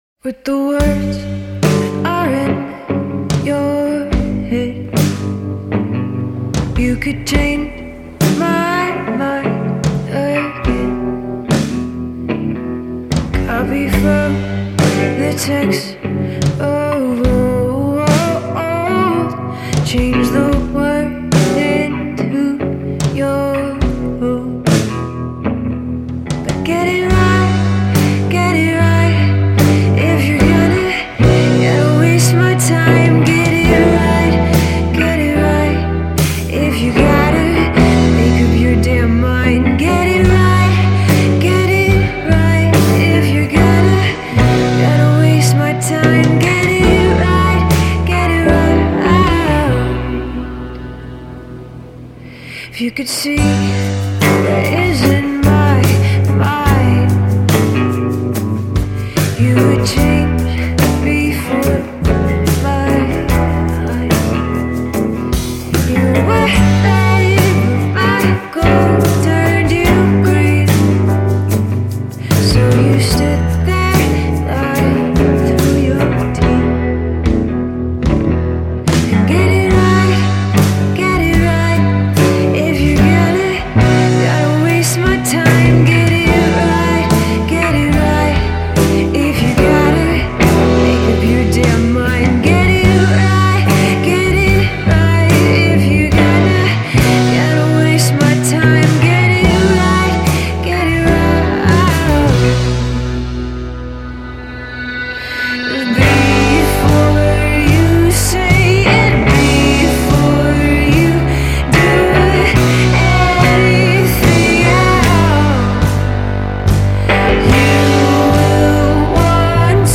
Brooklyn singer-songwriter